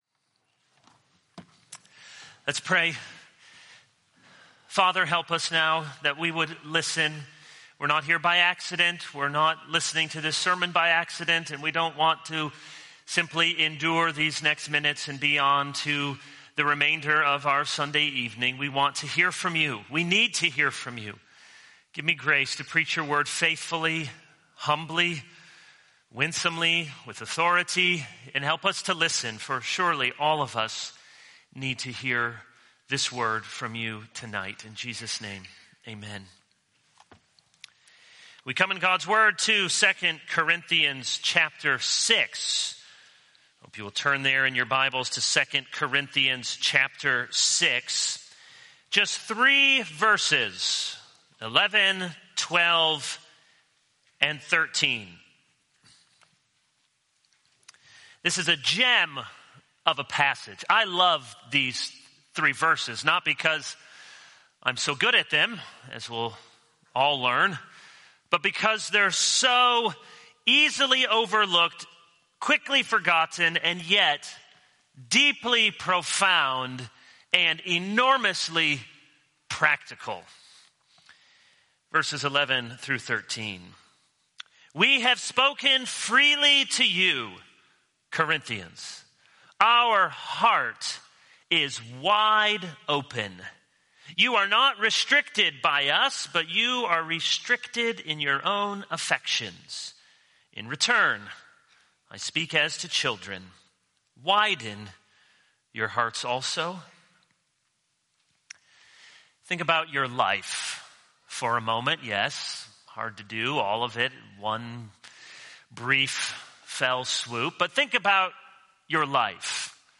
This is a sermon on 2 Corinthians 6:11-13.